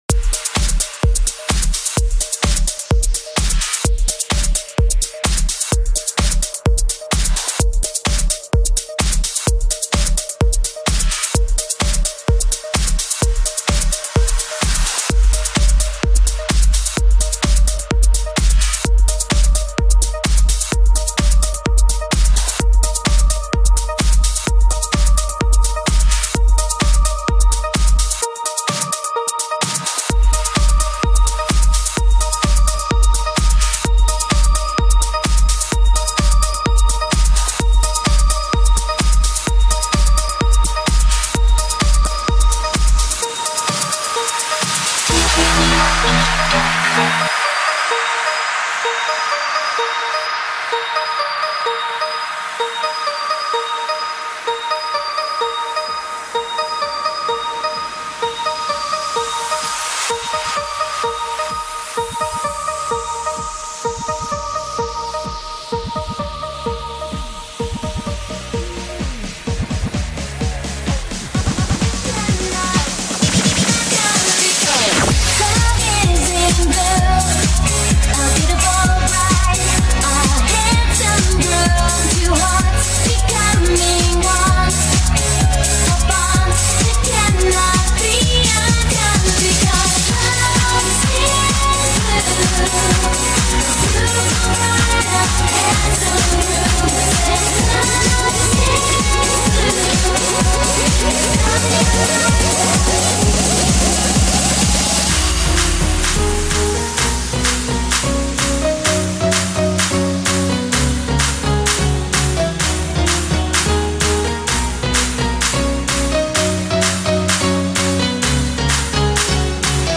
New remastered/reworked remix